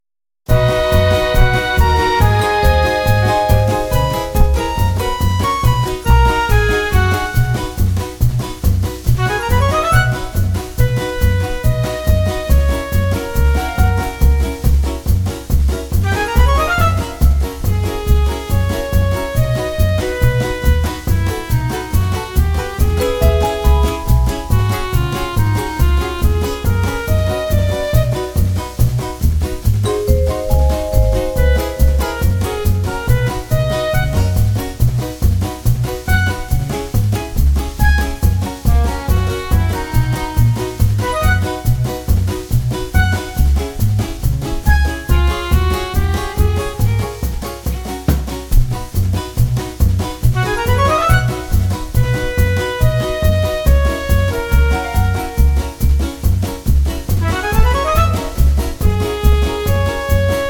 Instrumentale versie / karaoke